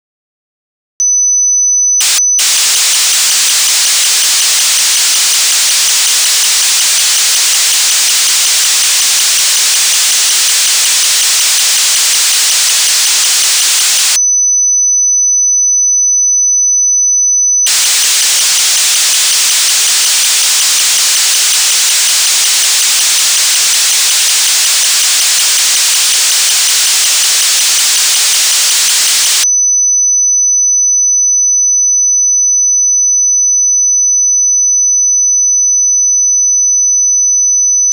FIRMWARE UPDATE v2.4 VIA AUDIO